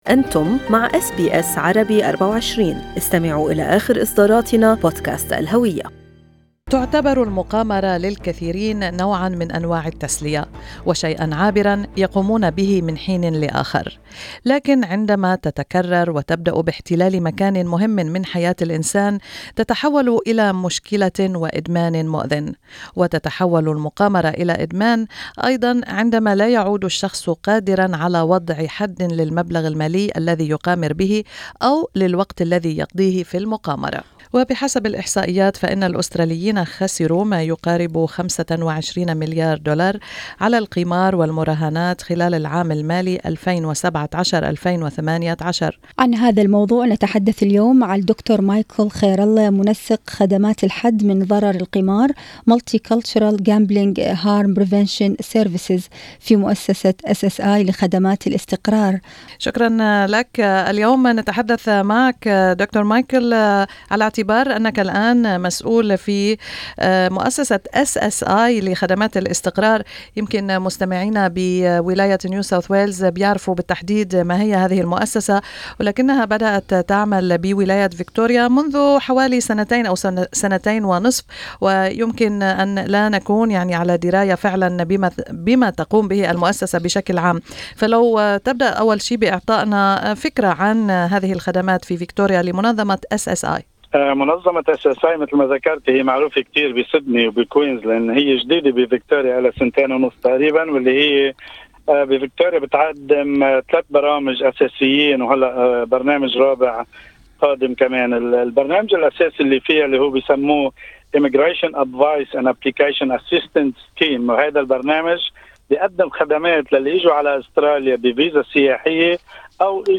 في حديث مع SBS Arabic24